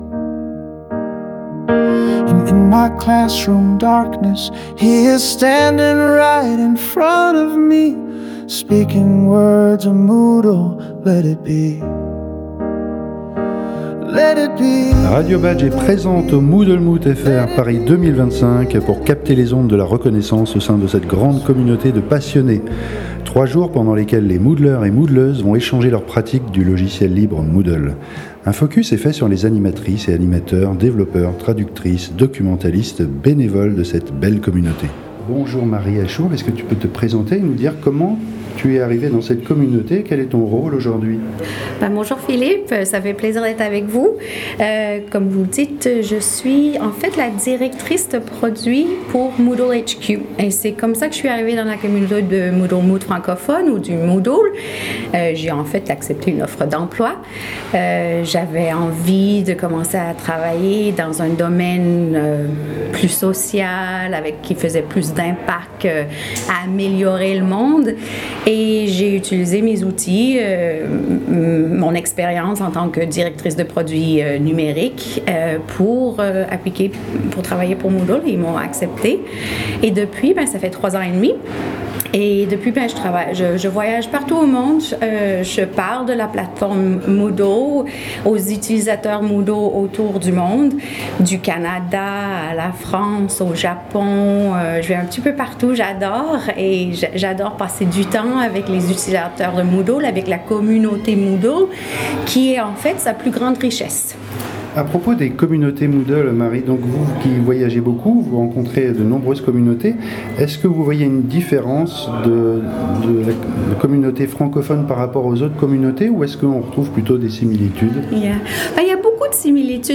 En direct du Moot